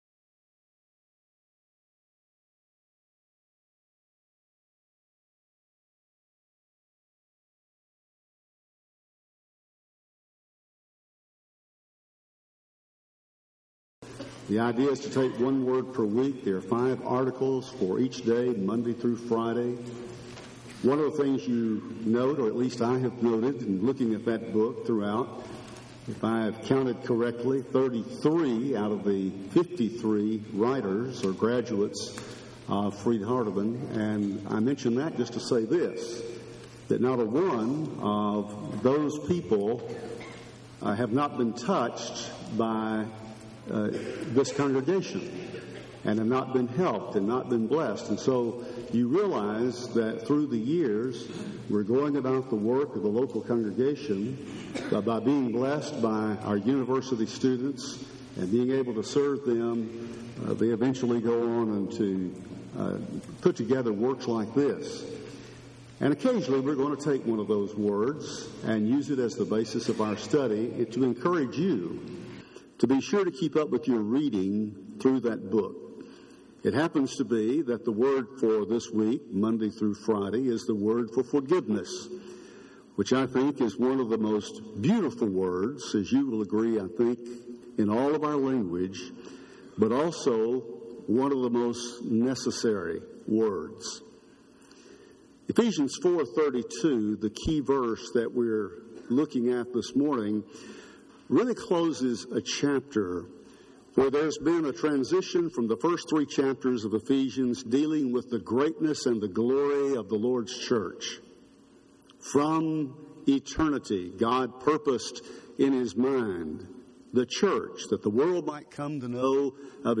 Forgiveness – Henderson, TN Church of Christ